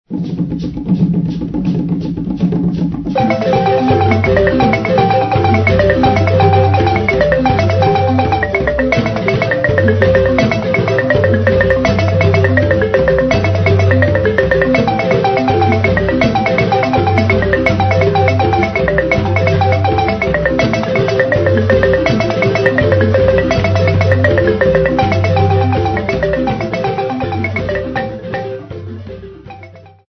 Folk music
Sacred music
Field recordings
Africa South Africa Kwanongoma sa
Indigenous folk instrumental, with a marimba xylophone and rattles.
96000Hz 24Bit Stereo